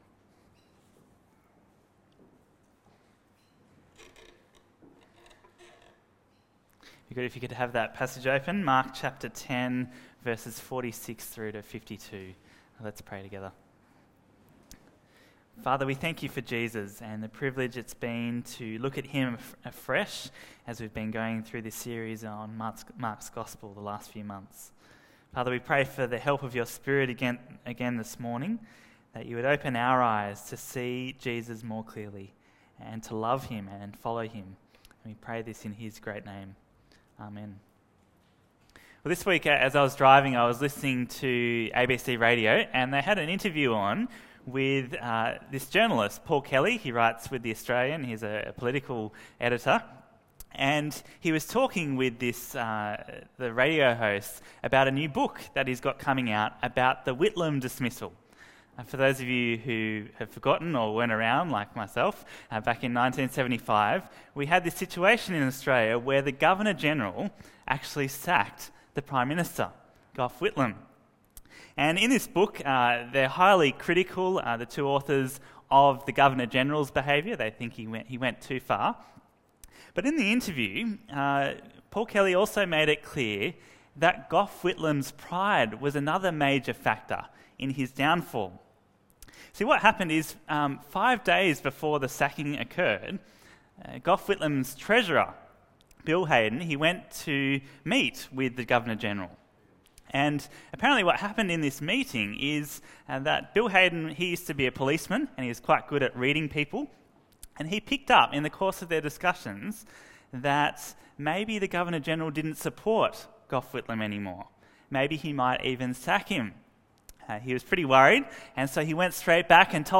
Bible Talks Bible Reading: Mark 10:46-52